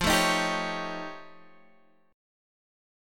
F+9 Chord
Listen to F+9 strummed